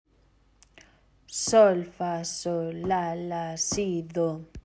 Cando os medimos, a sensación é que o compás queda curto, incompleto.
todo corcheas SOL-FA-SOL LA-LA SI-DO